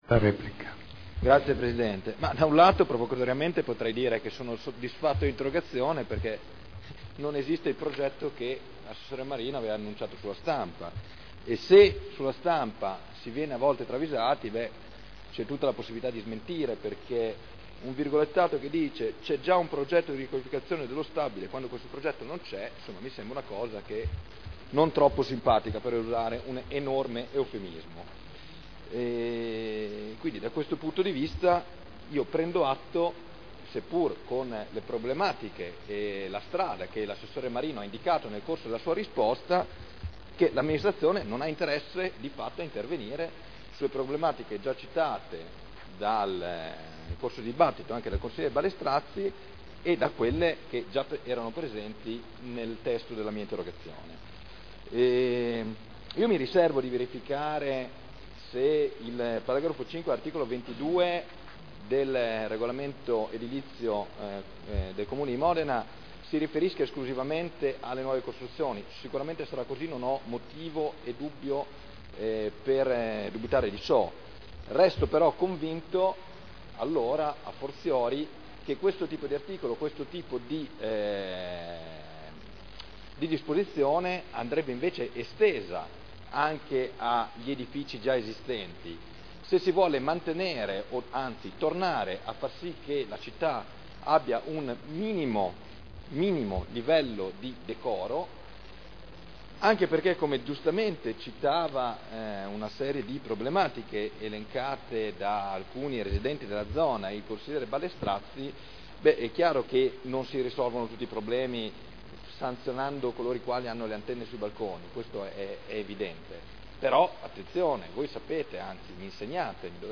Seduta del 10/01/2011. Dibattito su interrogazione del consigliere Barcaiuolo (PdL) avente per oggetto: “Riqualificazione Lambda”